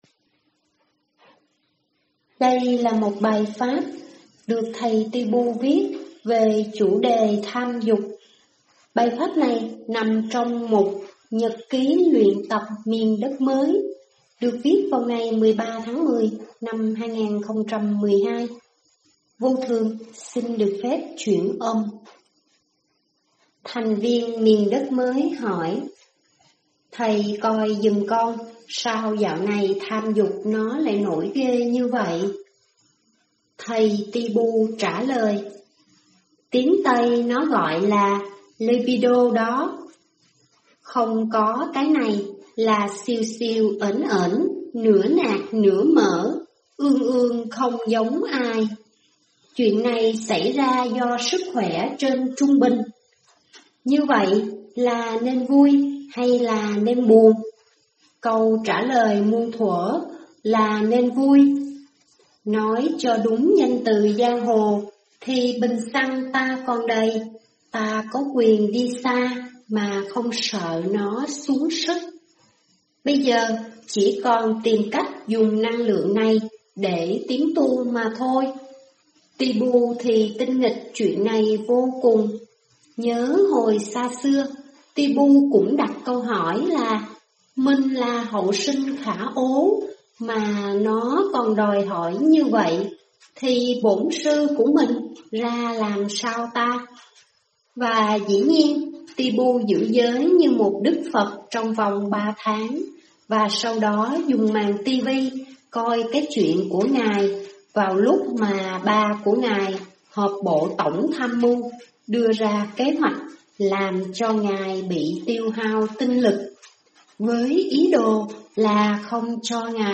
Sức khỏe, Tham dục và chuyện tu hành (chuyển âm)